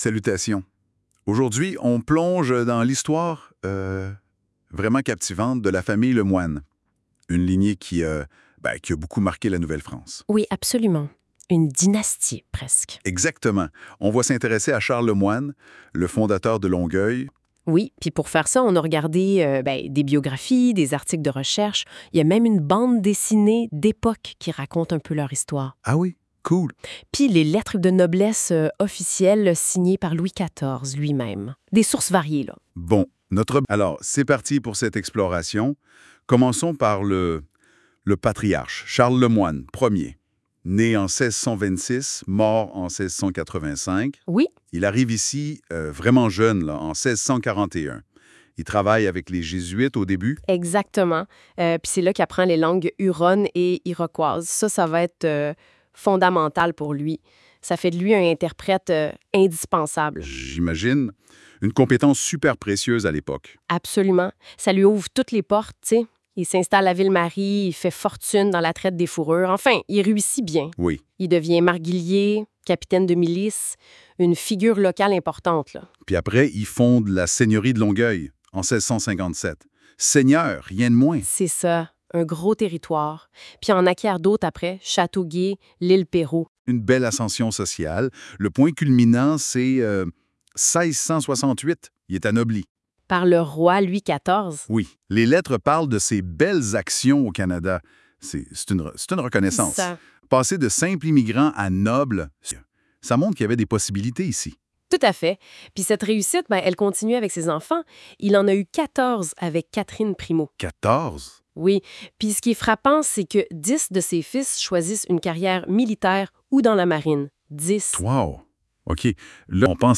Podcast (deux voix) de 3 minutes sur Charles Le Moyne